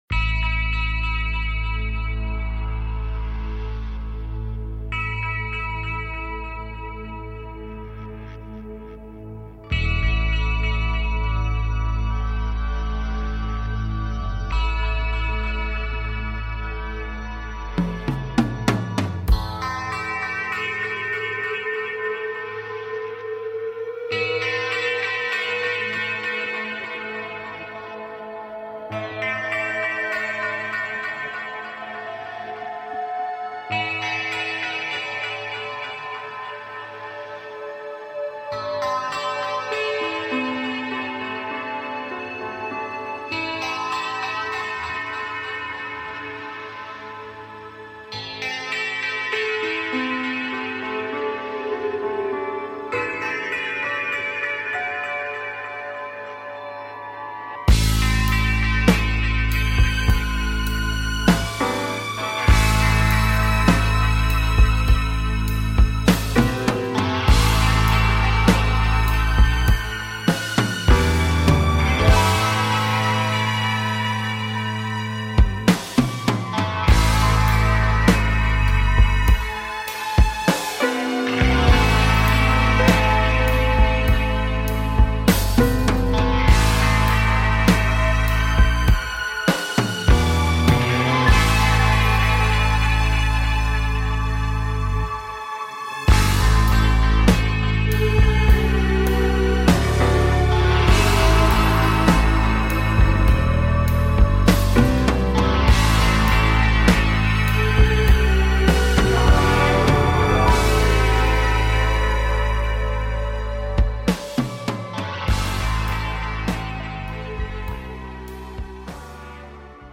Show on Homesteading and taking caller questions